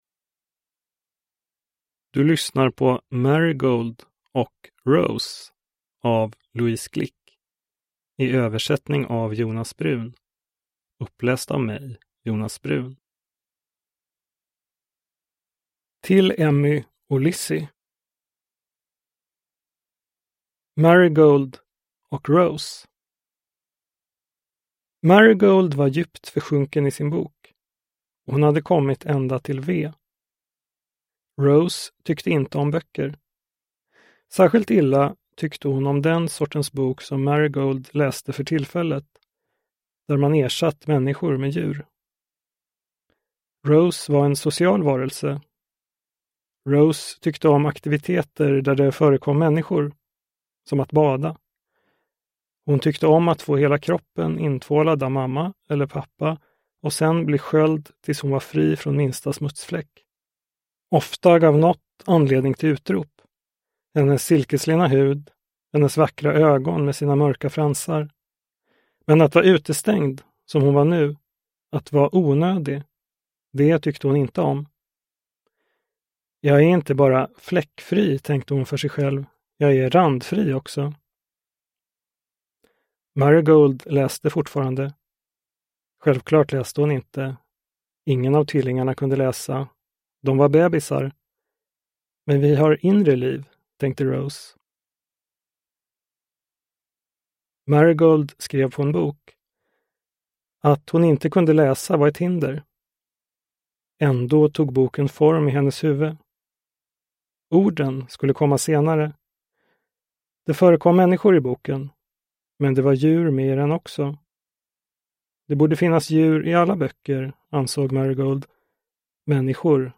Marigold och Rose – Ljudbok – Laddas ner